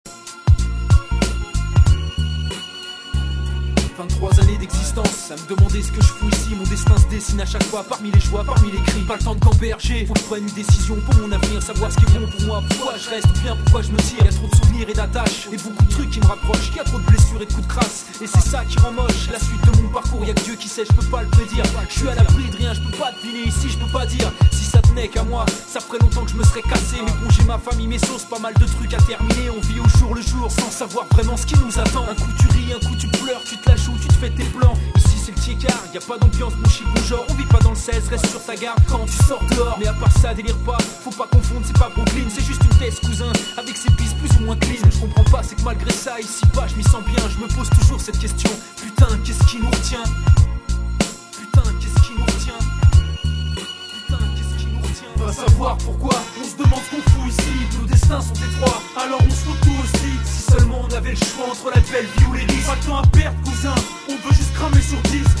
Trois chanteurs